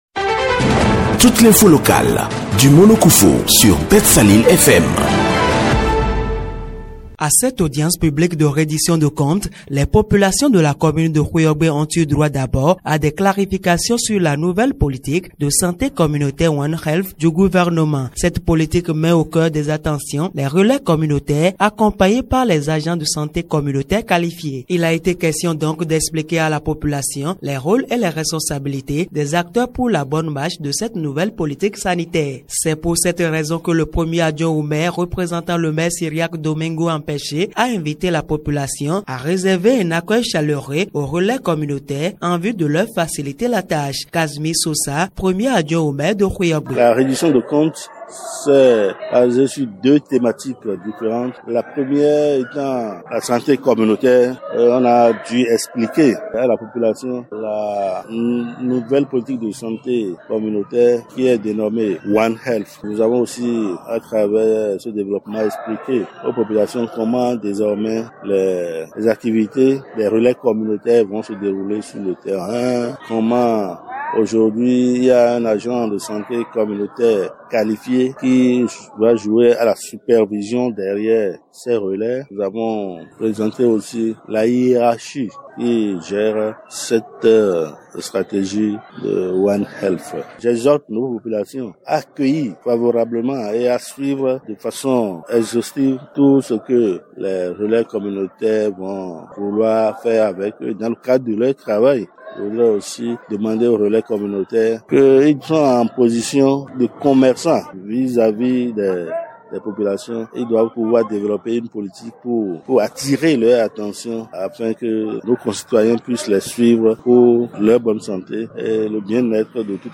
Voici le compte rendu